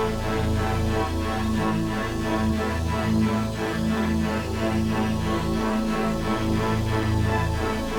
Index of /musicradar/dystopian-drone-samples/Tempo Loops/90bpm
DD_TempoDroneD_90-A.wav